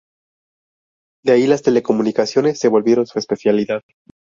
es‧pe‧cia‧li‧dad
Pronúnciase como (IPA)
/espeθjaliˈdad/